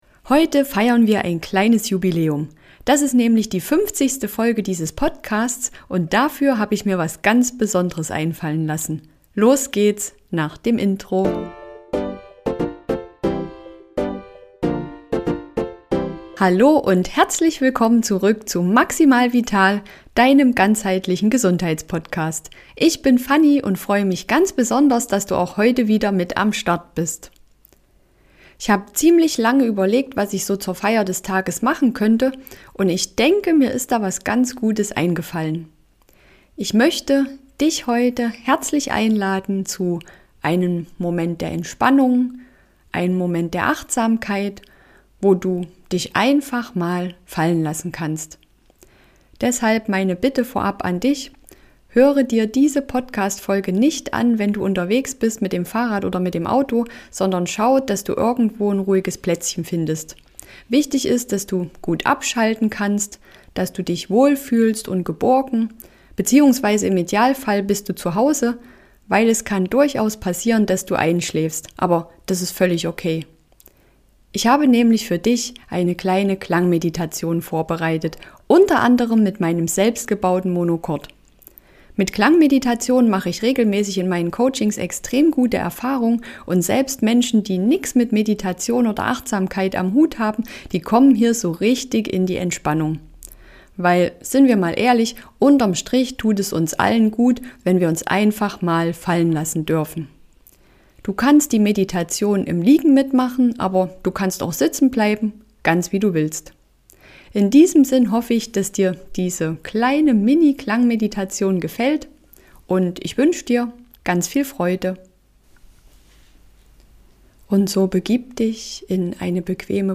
Diese Klangmeditation mit Windspielen, Monochord und Klangschalen führt dich sanft in die Entspannung und hilft dir beim Einschlafen. Geeignet ist die Meditation für Anfänger und Fortgeschrittene - und zwar täglich.